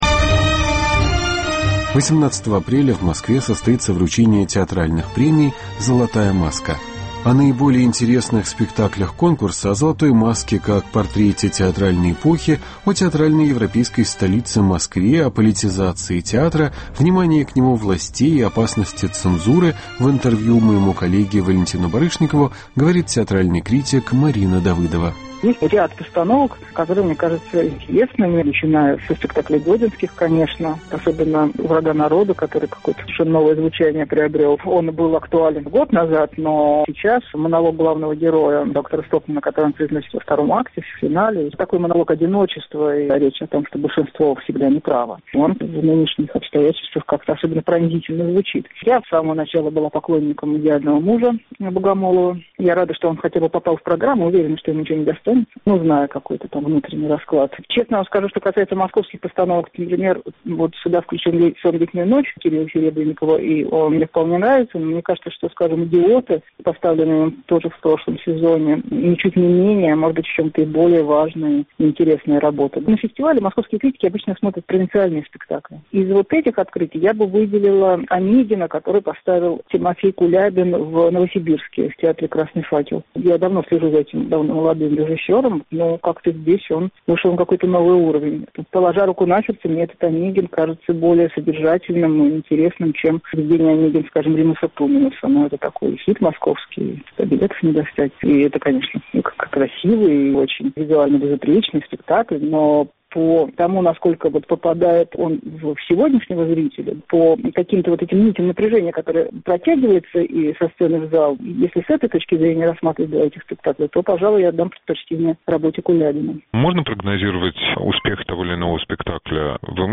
Разговор с театральным критиком Мариной Давыдовой